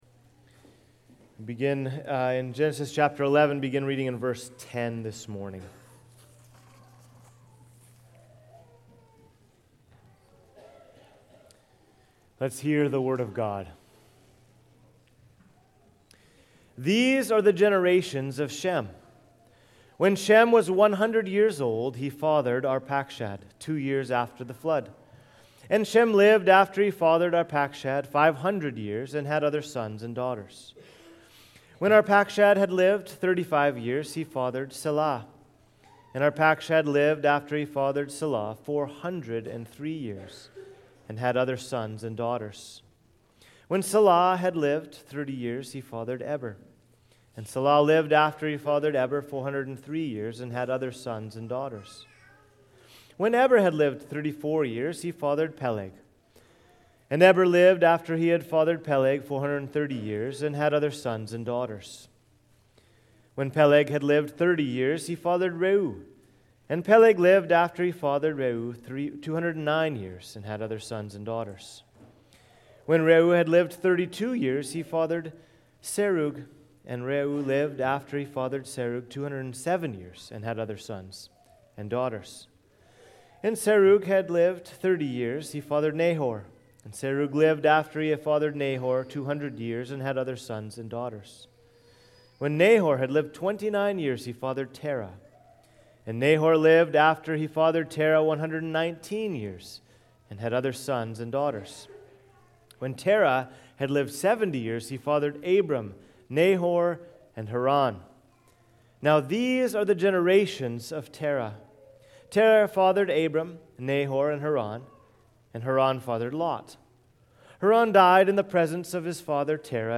Sermons | Cornerstone Bible Church